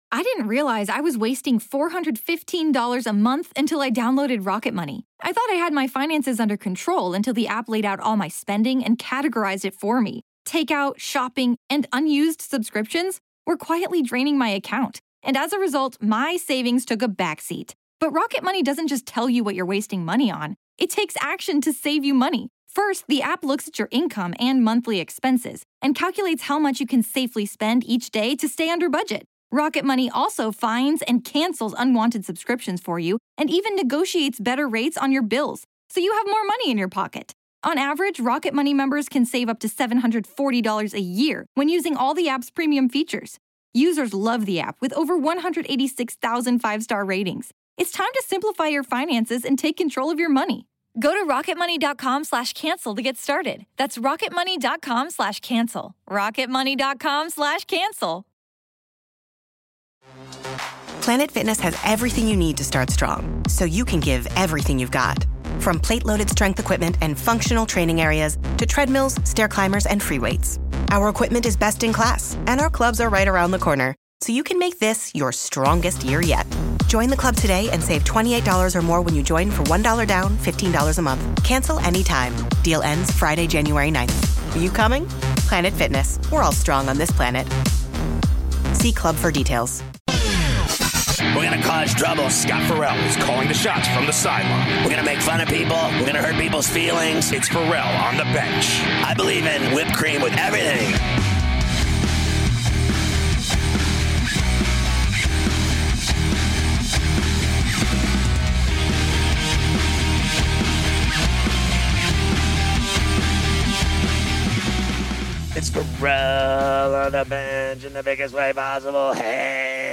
Scott Ferrall comes on LIVE from the Super Bowl Media Center in LA and talks about all going on there and talks about everything in sports